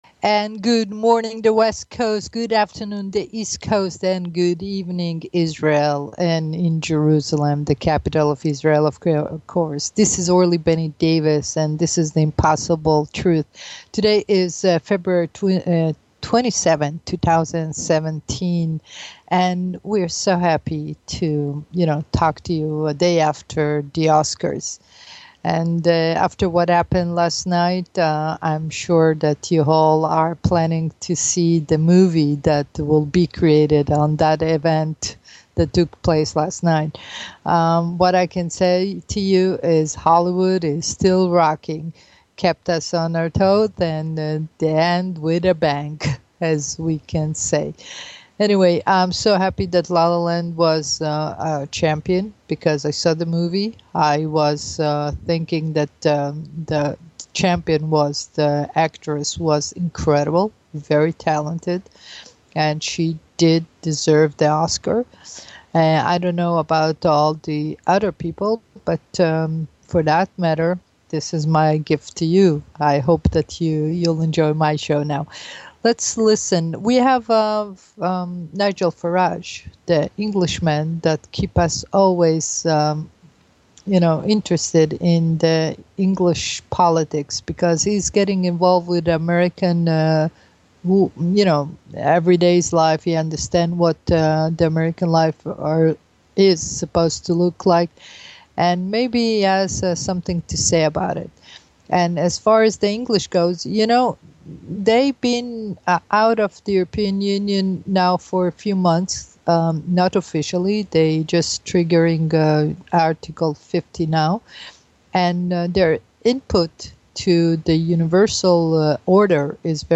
The Impossible Truth on BBS Radio.